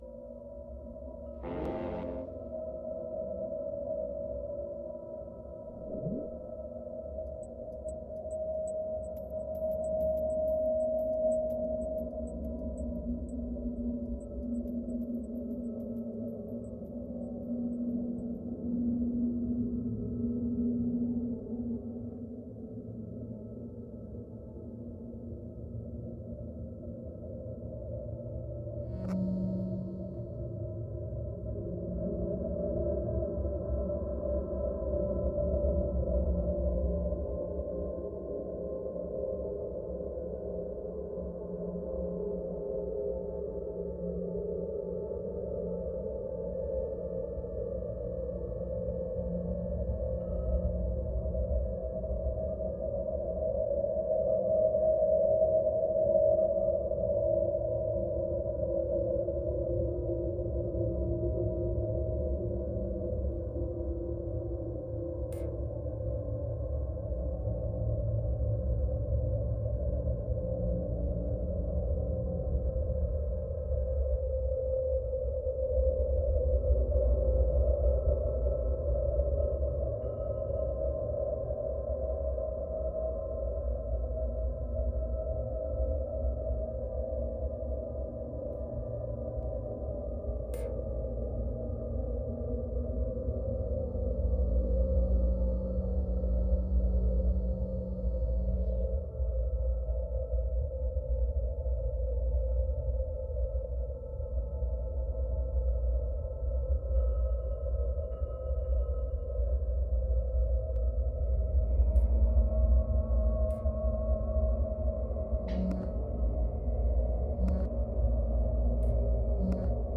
Audio: Soundtrack mixdown tests